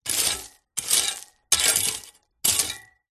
Звуки сада
Металлические садовые грабли для гравия версия 2